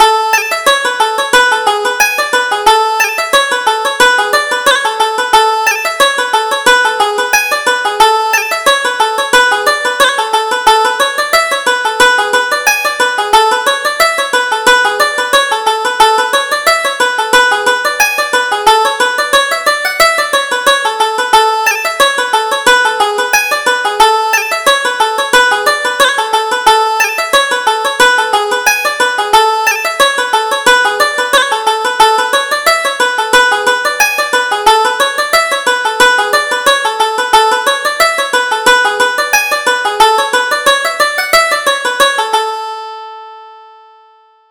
Reel: The Green Linnet